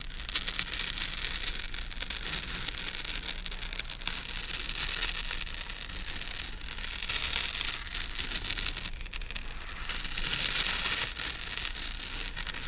Fuse Burn On Wood, Crackley, Loop